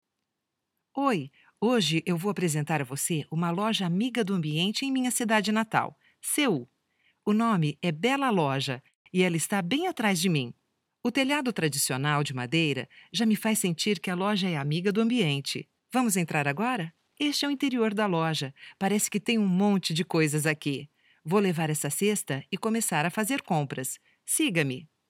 Voiceover Portugues Brasileiro, voiceover talent.
Sprechprobe: Werbung (Muttersprache):